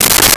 Plastic Lid
Plastic Lid.wav